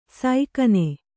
♪ saikane